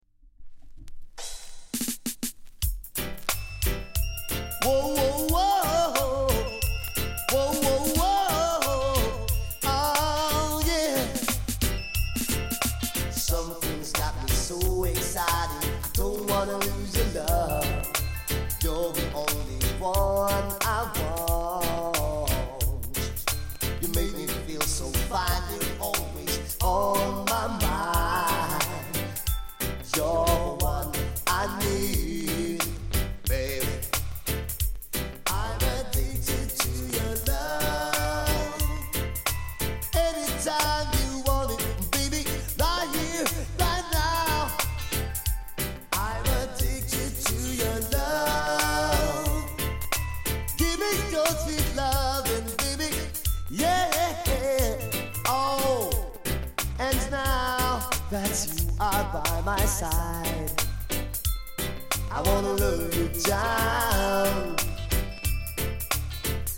R＆B～LOVERS
音に影響ない 軽い反り。